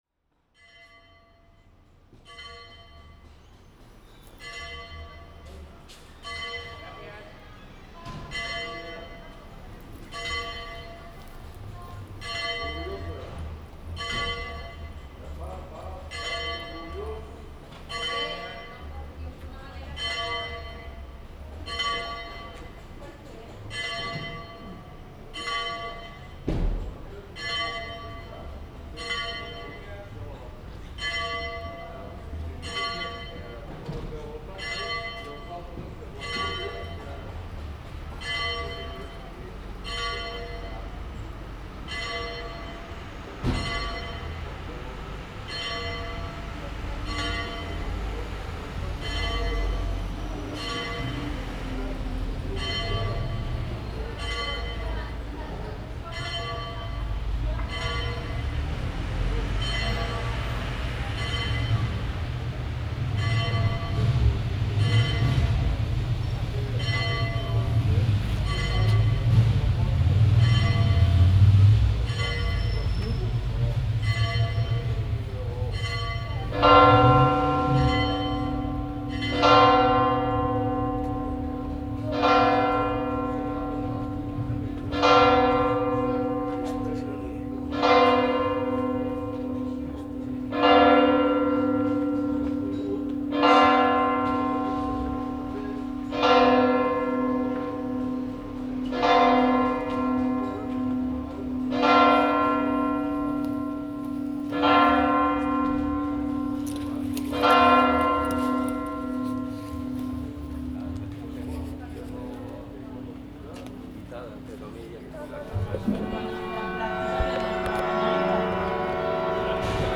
31.3 CAMPANES VIGÍLIA Grup de Campaners de la Catedral